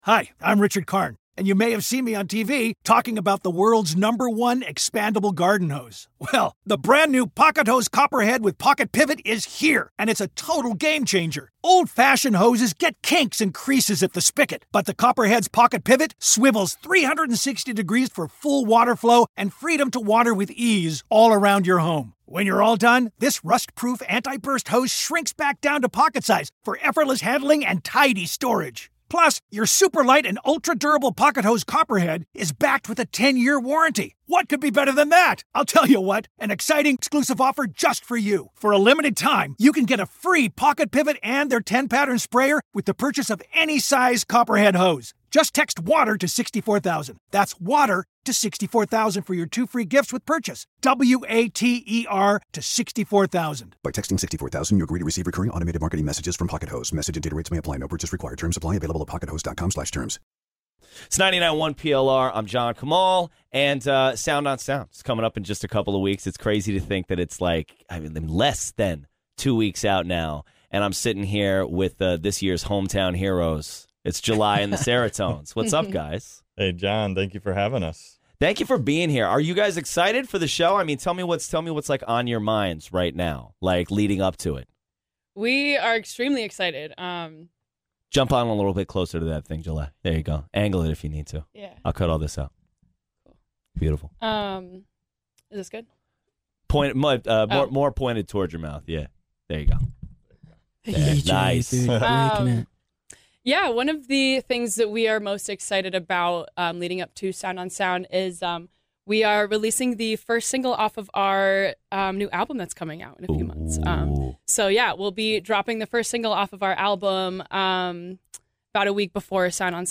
sat down and spoke to the band about their sound, their songwriting and which band member is considered the "mitochondria"!